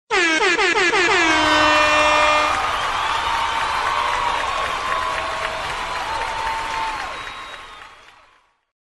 Airhorn Applause Efeito Sonoro: Soundboard Botão
Airhorn Applause Botão de Som